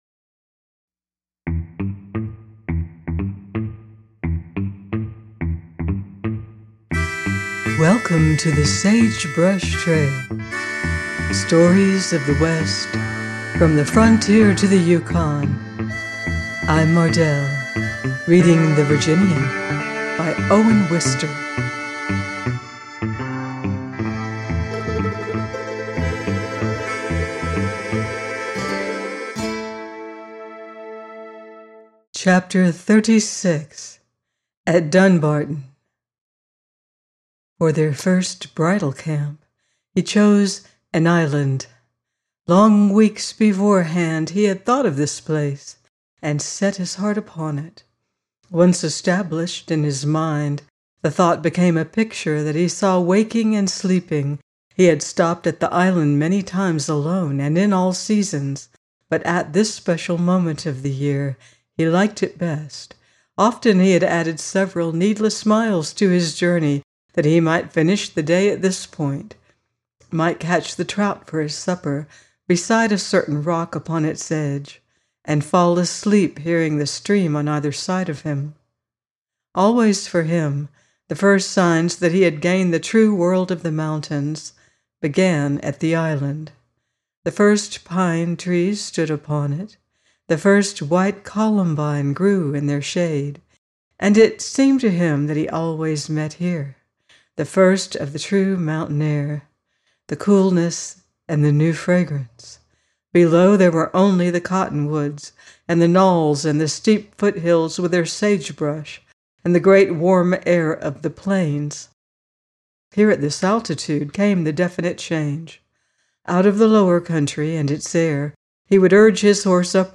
The Virginian - by Owen Wister - audiobook